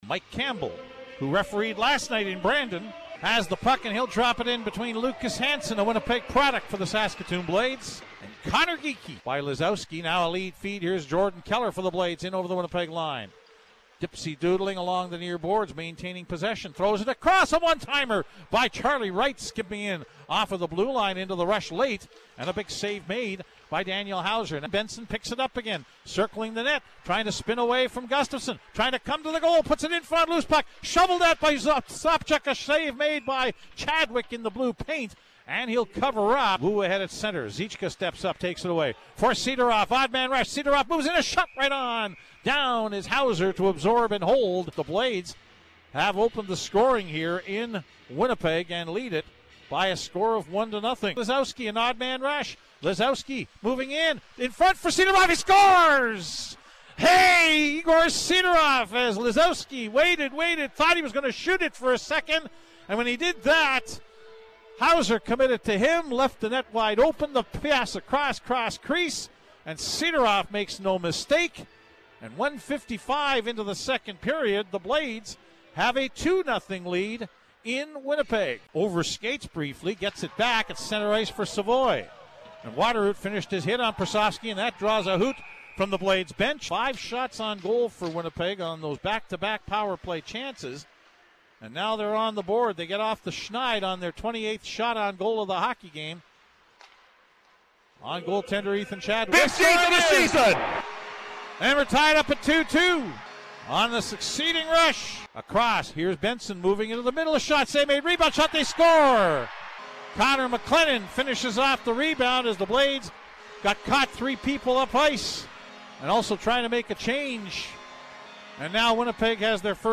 Following is the audio highlights from CJWW’s broadcast